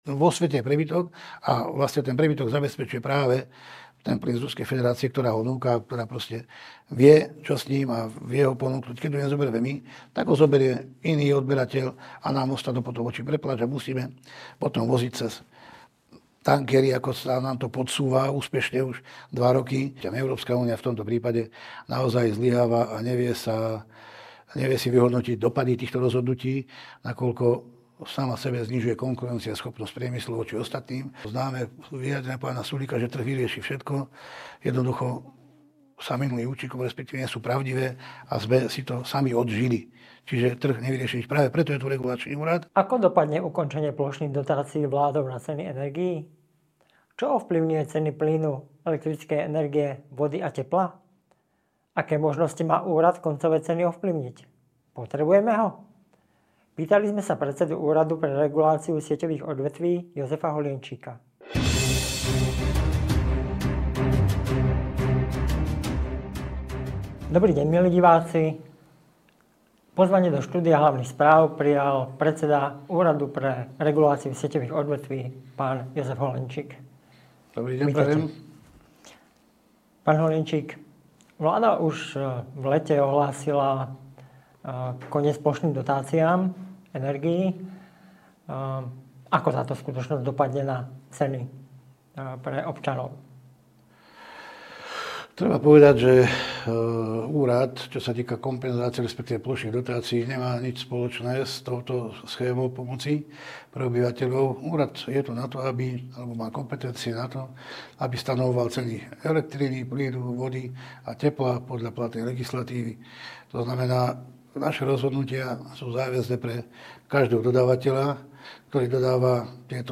Predseda ÚRSO v rozhovore pre HS naznačil, aký vietor zažijú v peňaženkách domácnosti kvôli energiám